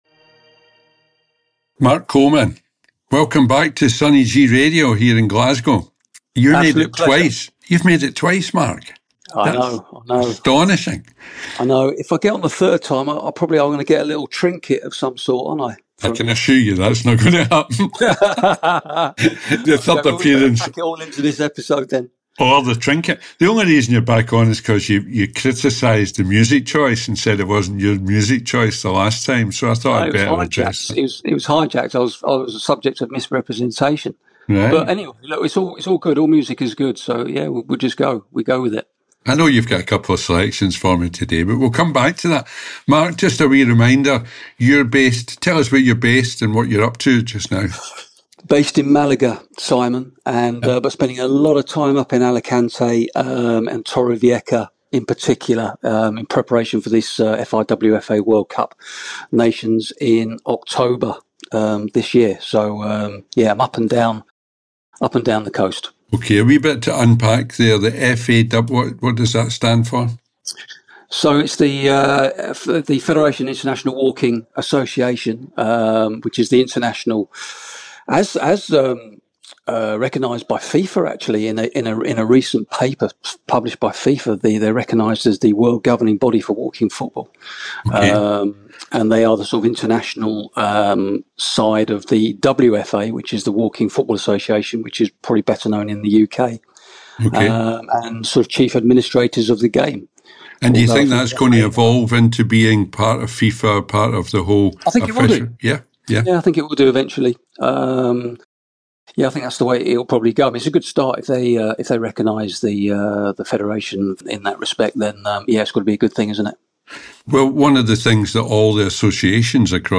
interviews
on Sunny G radio, they take all about Walking Football and the World Nations Cup.